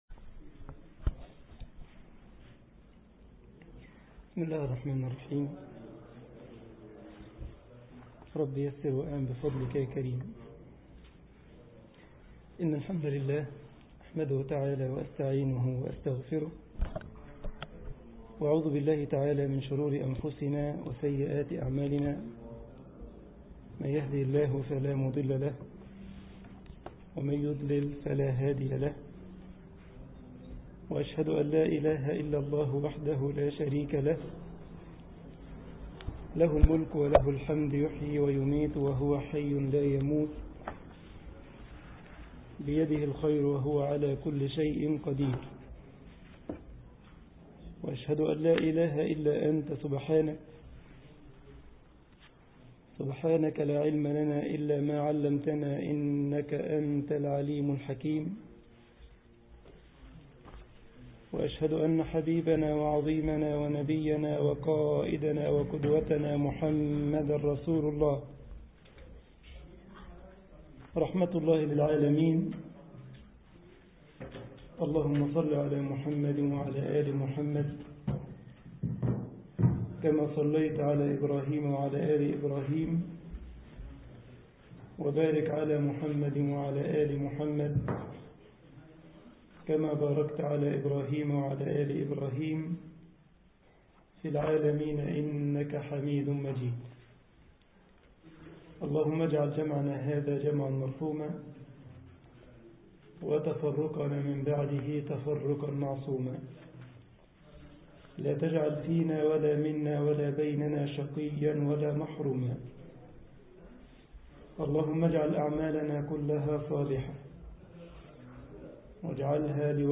مسجد الجمعية الإسلامية بكايزرسلاوترن ـ ألمانيا